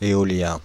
Ääntäminen
France (Île-de-France): IPA: /e.ɔ.ljɛ̃/ Paris: IPA: [e.ɔ.ljɛ̃]